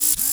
ROBOTIC_Servo_Medium_Mid-Movement_mono.wav